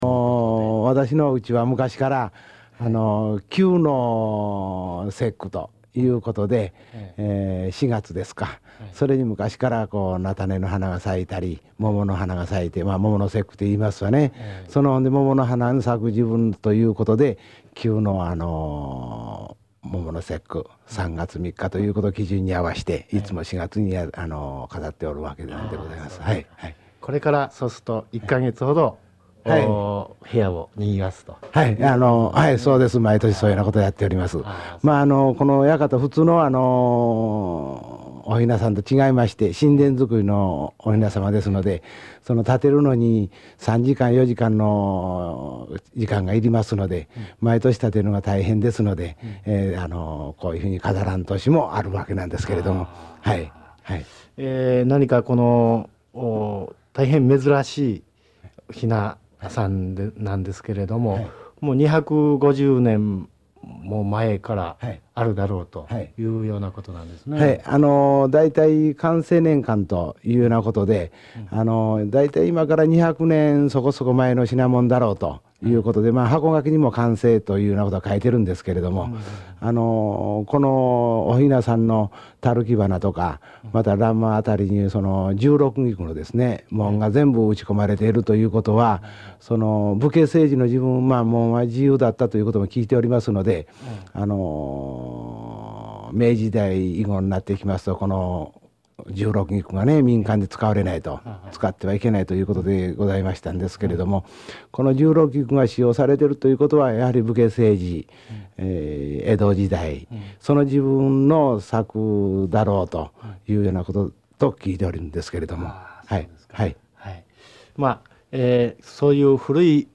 さてそれがどんなお雛様か、お話に耳を傾けてみましょう。